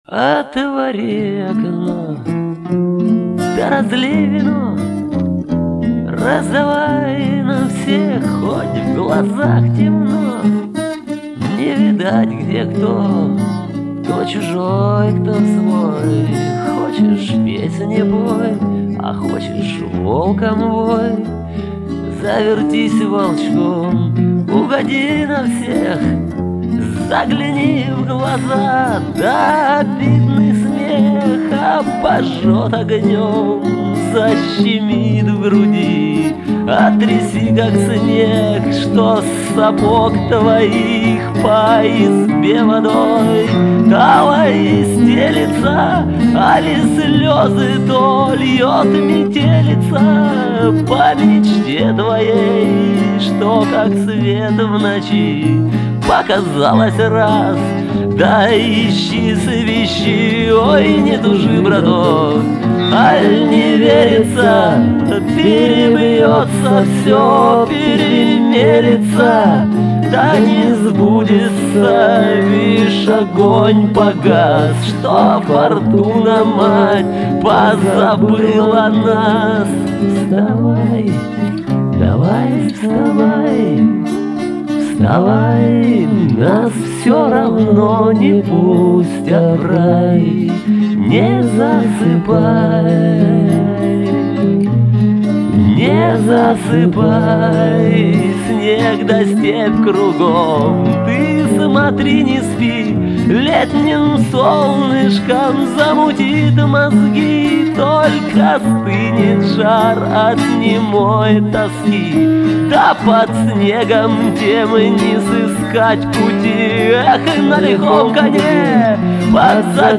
вокал, ак. гитара